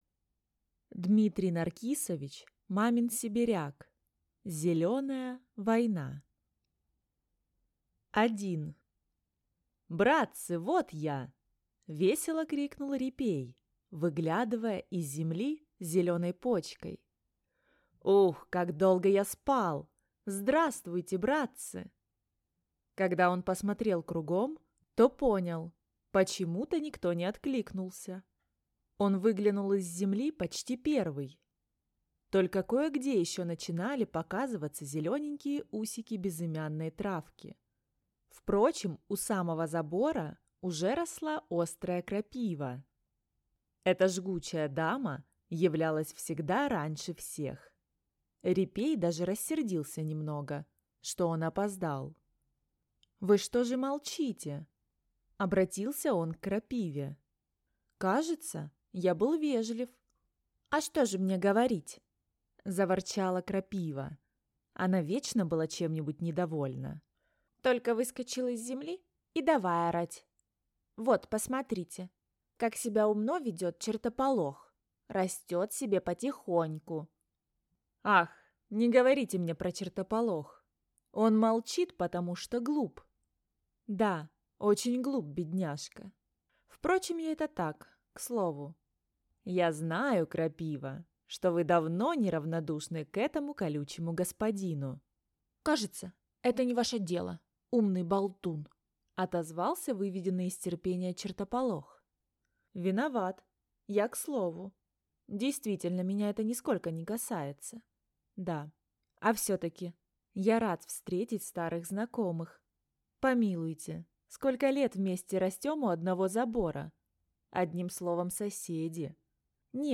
Аудиокнига Зеленая война | Библиотека аудиокниг
Читает аудиокнигу